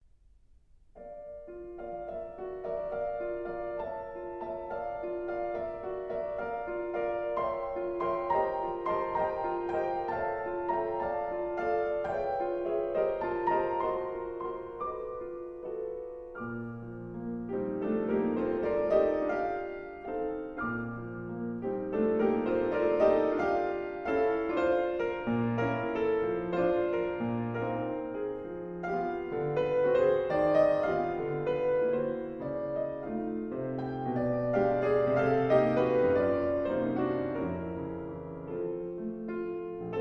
Prelude No. 17 in A flat major: Andante molto espressivo